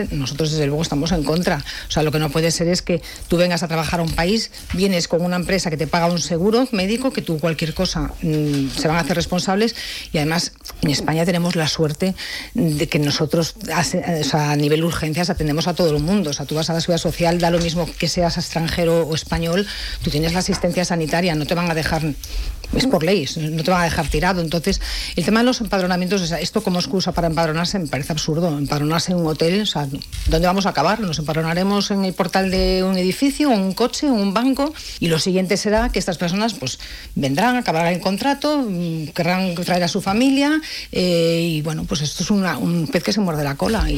La regidora del Partit Popular de Calella, Celine Coronil, ha fet balanç de l’actualitat local en una entrevista al matinal de RCT on ha abordat temes clau com el turisme, el comerç local i neteja.